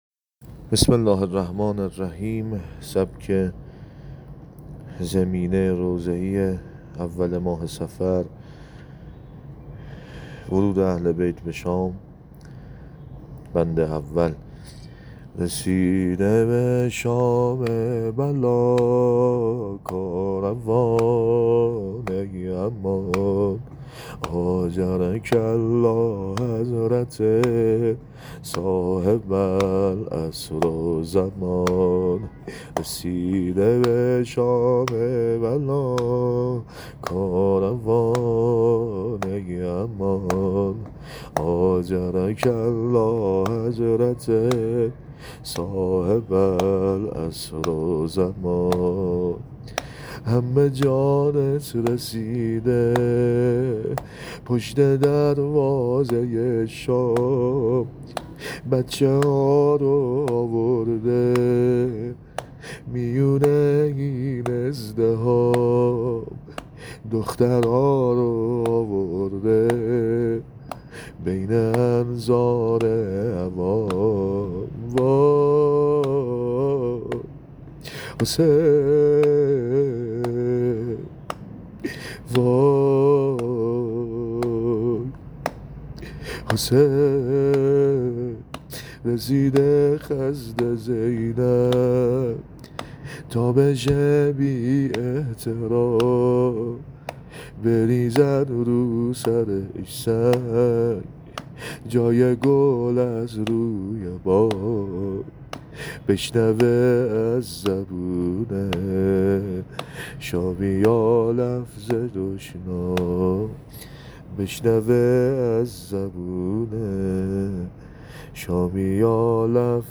عنوان : زمینه اول ماه صفر